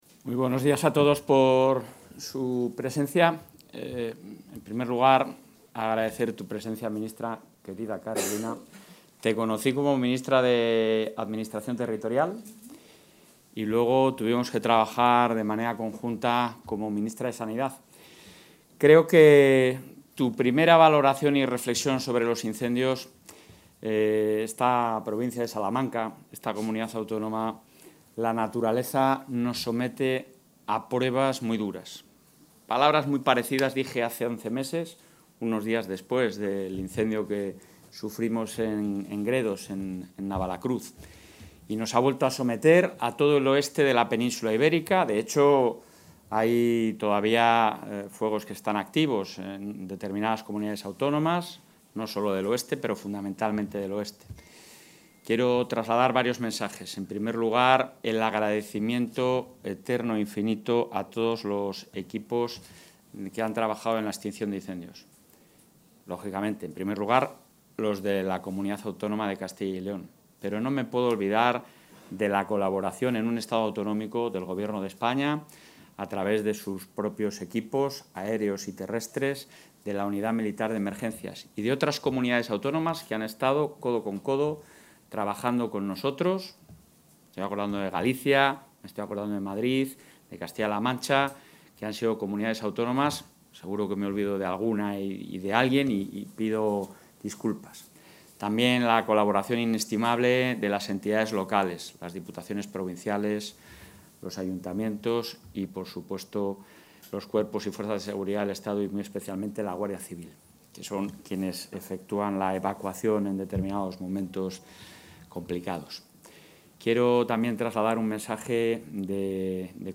Intervención presidente de la Junta.